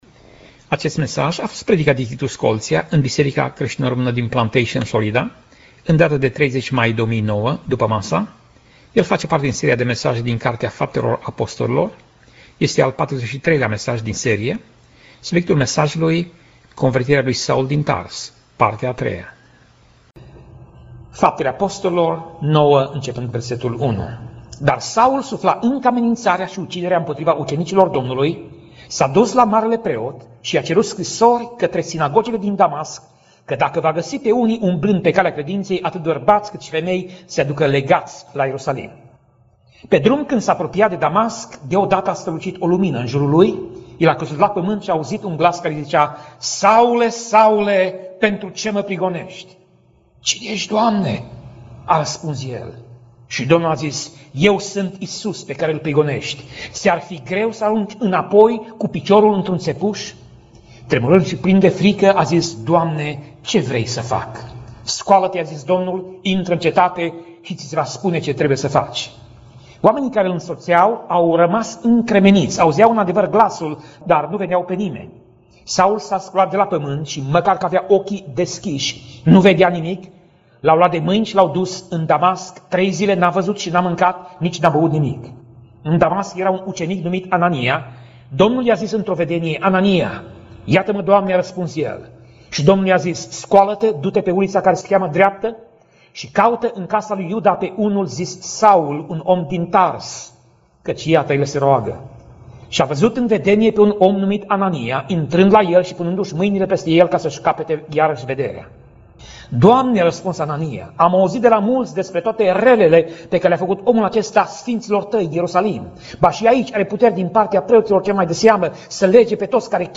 Pasaj Biblie: Faptele Apostolilor 9:1 - Faptele Apostolilor 9:22 Tip Mesaj: Predica